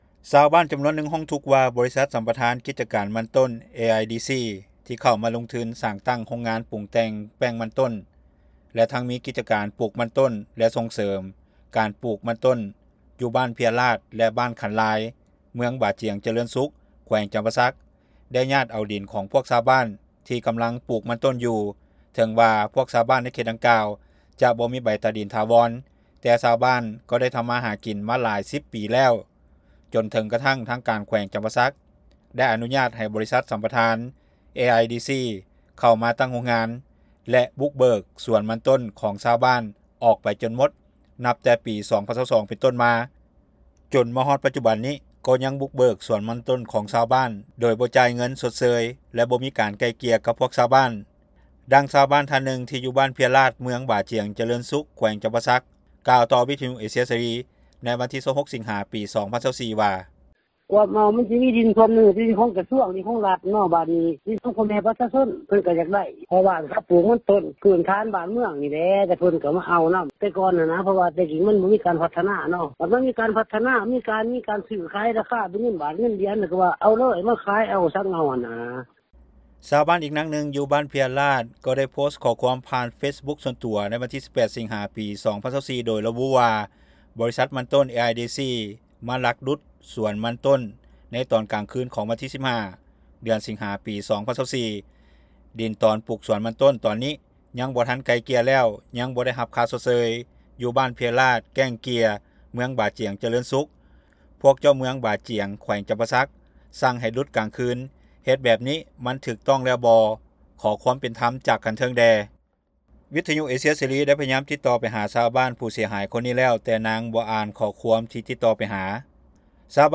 ດັ່ງຊາວບ້ານທ່ານໜຶ່ງຢູ່ບ້ານເພຍລາດ ເມືອງບາຈຽງຈະເລີນສຸກ ແຂວງຈຳປາສັກ ກ່າວຕໍ່ວິທຍຸເອເຊັຽເສຣີ ໃນວັນທີ 26 ສິງຫາ 2024 ວ່າ:
ຊາວບ້ານອີກນາງໜຶ່ງ ຢູ່ບ້ານຂັນລາຍ ເມືອງບາຈຽງຈະເລີນສຸກ ກ່າວວ່າບໍ່ຮູ້ລາຍລະອຽດ ທີ່ຈະແຈ້ງປານໃດແຕ່ກາພໍຮູ້ຂ່າວວ່າ ບໍລິສັດສຳປະທານມັນຕົ້ນ AIDC ເຂົ້າມາສຳປະທານ ເອົາດິນຂອງຊາວບ້ານໄປຈົນໝົດເຮັດໃຫ້ຊາວບ້ານ ຈຳນວນຫຼາຍພາກັນຈົ່ມ ແລະ ເກີດຄວາມບໍ່ພໍໃຈໂດຍສະເພາະ ເລື່ອງການໄປດຸດສວນມັນຕົ້ນ ຂອງຊາວບ້ານຖີ້ມໂດຍບໍ່ມີການໄກ່ເກັ່ຍ ແລະຈ່າຍຄ່າຊົດເຊີຍໃຫ້ກັບຊາວບ້ານເລົ່ານັ້ນ. ດັ່ງຊາວບ້ານນາງນີ້ ກ່າວຕໍ່ວິທຍຸເອເຊັຽເສຣີ ໃນມື້ດຽວກັນນີ້ວ່າ:
ດັ່ງເຈົ້າໜ້າທີ່ທ່ານນີ້ກ່າວຕໍ່ວິທຍຸເອເຊັຽເສຣີໃນມື້ດຽວກັນນີ້ວ່າ: